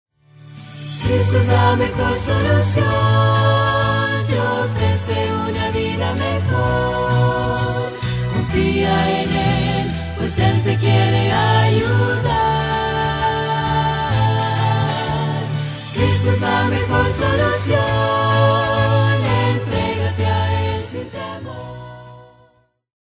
Demos